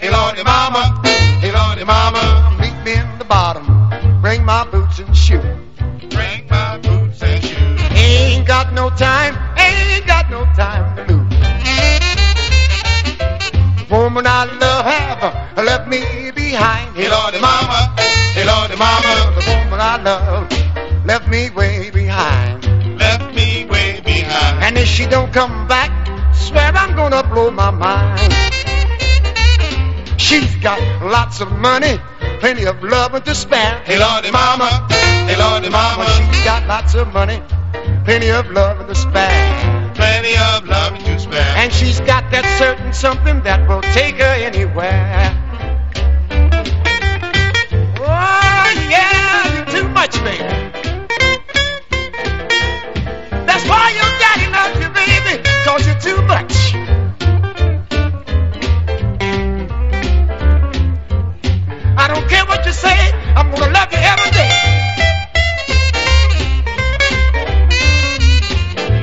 SOUL / SOUL / NORTHERN SOUL / FREE SOUL / MODERN SOUL